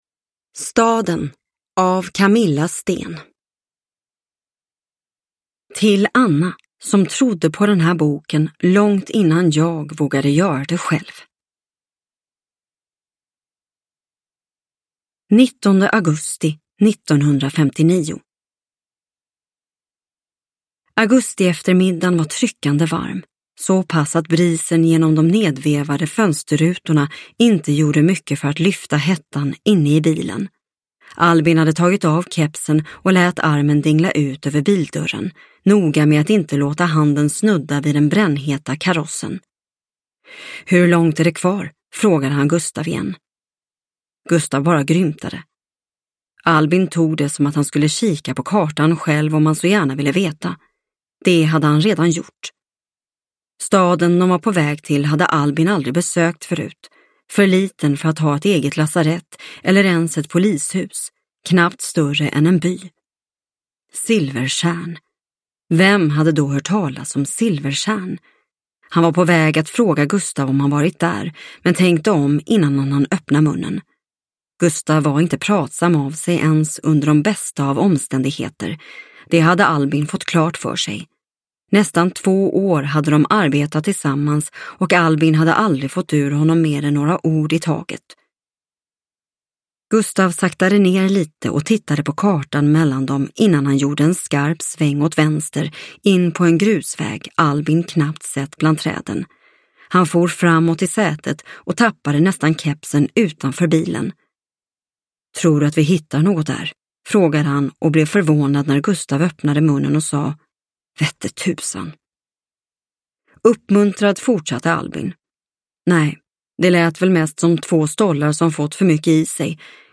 Staden – Ljudbok – Laddas ner